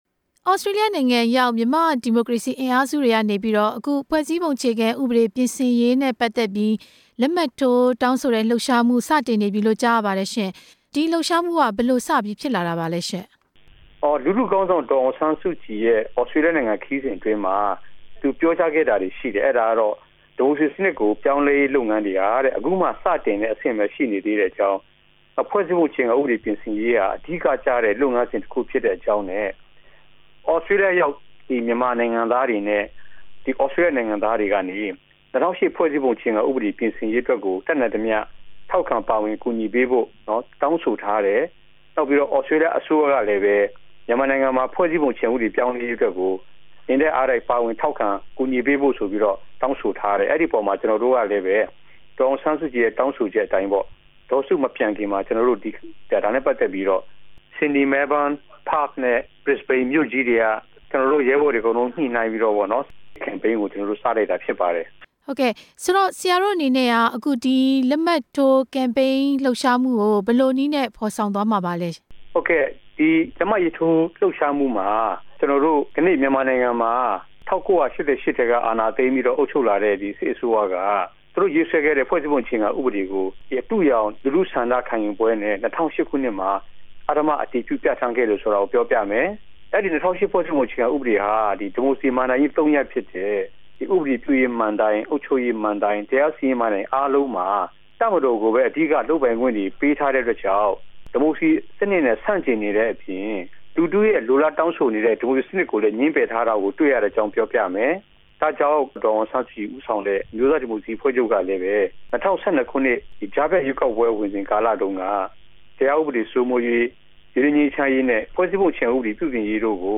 ဆက်သွယ်မေးမြန်းပြီး တင်ပြထားပါတယ်။